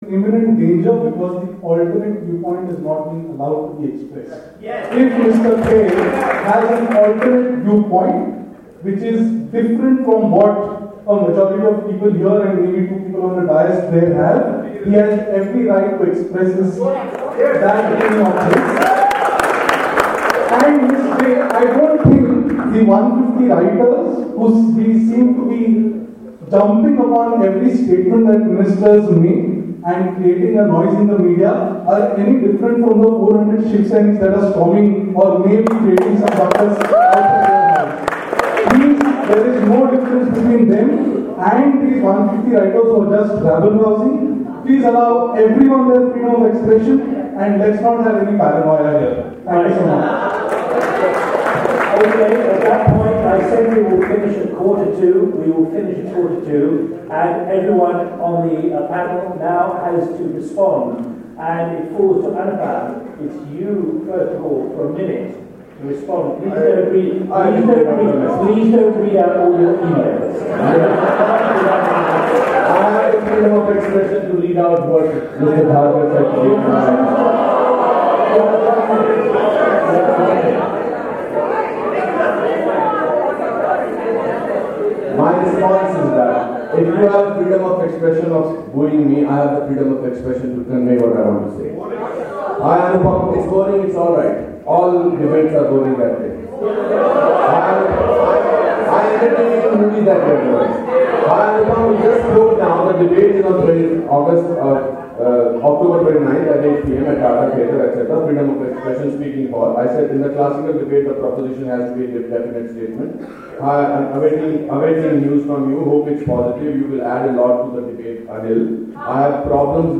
Tata LitLive full debate: It was at a debate on 'Freedom of expression is in imminent danger,' where actor Anupam Kher and Nalin Kohli of the BJP were speaking against the motion, facing off with celebrated writers Shobhaa De and Sudheendra Kulkarni who spoke for it.